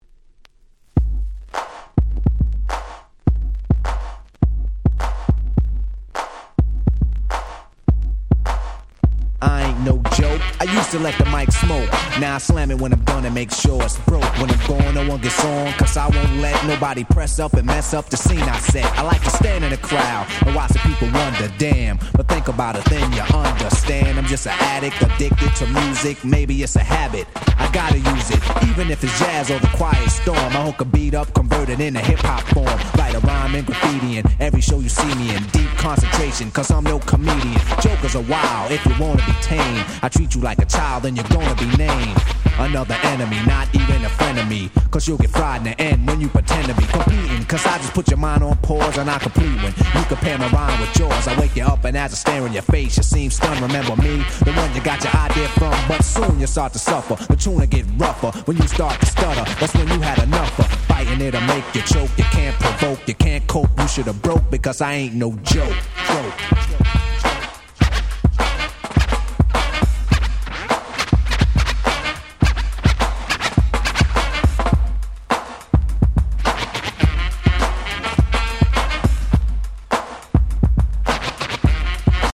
87' Hip Hop Super Classics !!
説明不要のOld School / Middle Schoolスーパークラシックス！！
再発盤ですが音質バッチリ！！